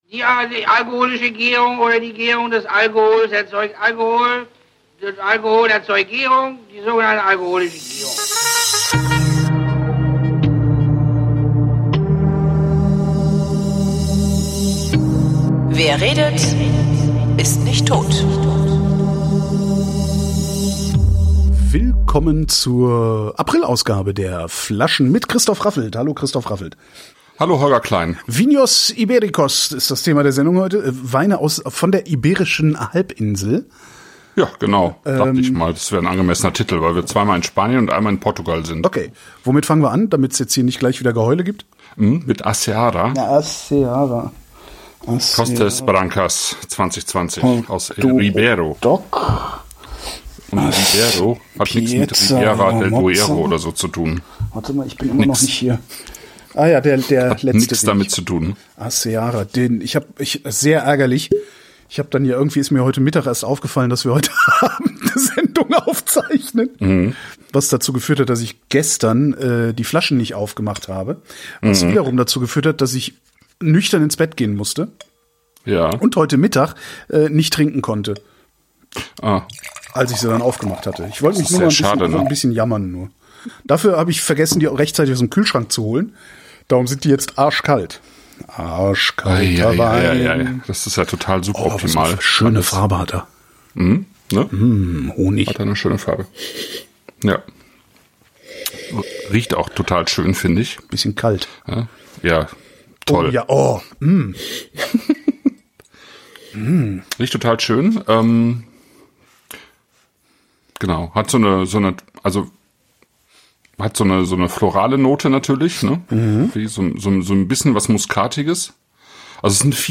Wir trinken Iria Otero, A Seara Blanco, Ribeiro 2020, Baldovar 923, Pieza la Moza Rosado, Valencia 2020, Pormenor, Douro Tinto 2019 und reden. Unter anderem über Marmelade und Sherry.